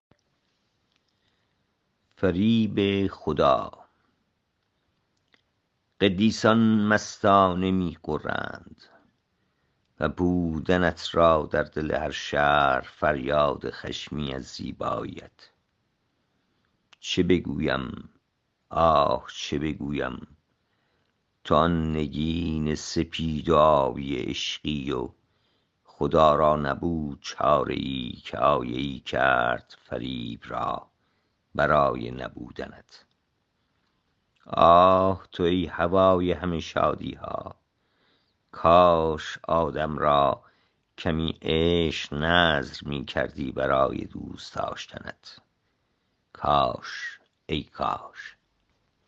این شعر را با صدای شاعر از این جا بشنوید